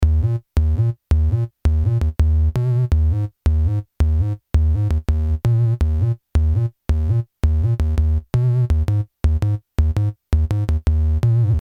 弯音驱动合成器循环
标签： 83 bpm Rap Loops Synth Loops 1.95 MB wav Key : Unknown
声道立体声